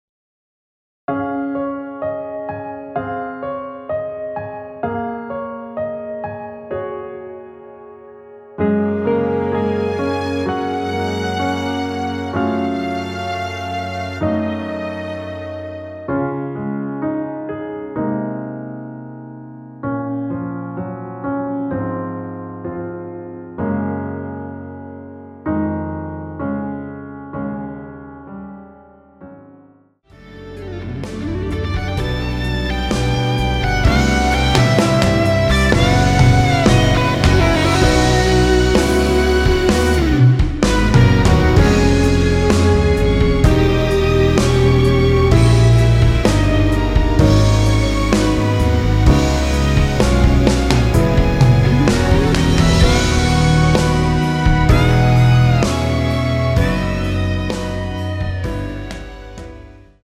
원키에서(-2)내린 MR입니다.
Db
앞부분30초, 뒷부분30초씩 편집해서 올려 드리고 있습니다.